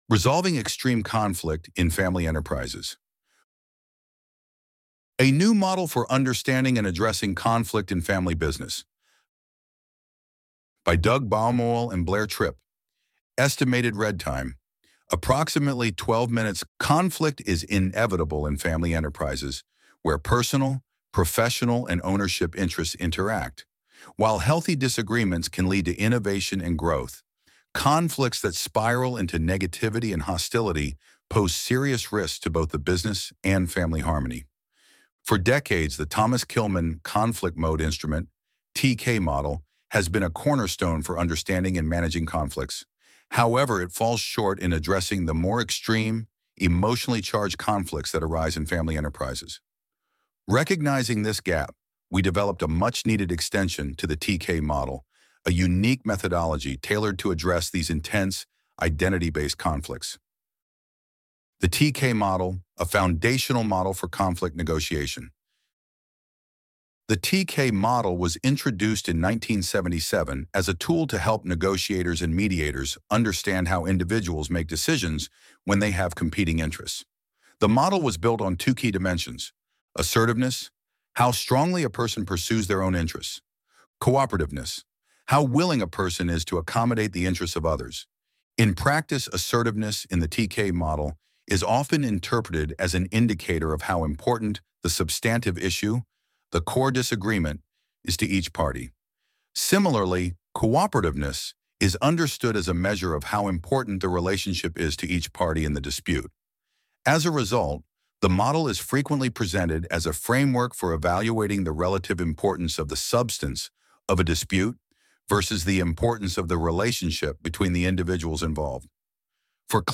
Resolving Extreme Conflict in Family Enterprises A New Model for Understanding and Addressing Conflict in Family Business Loading the Elevenlabs Text to Speech AudioNative Player...